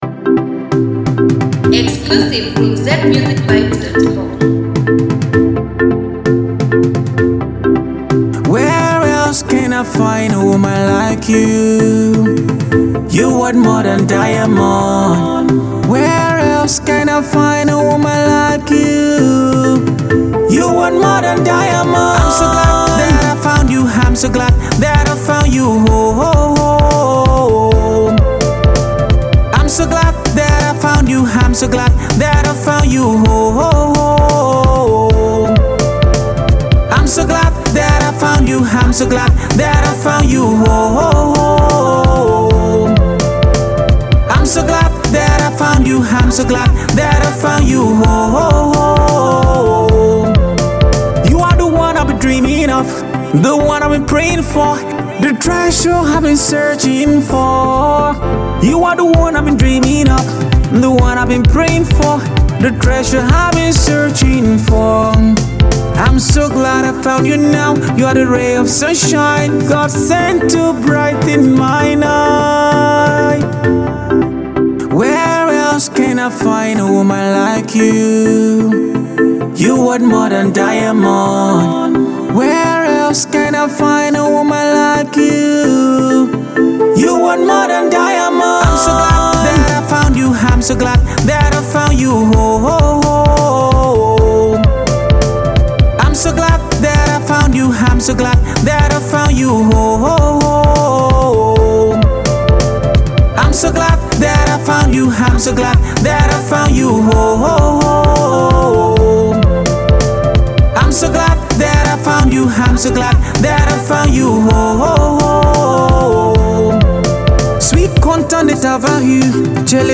wedding songs